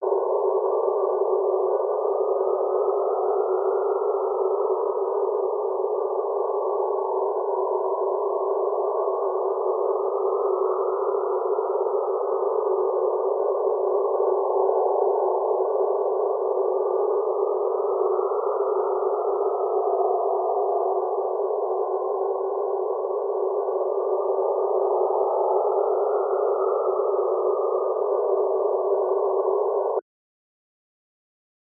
X-ray light from IXPE is heard as a continuous range of frequencies, producing a wind-like sound.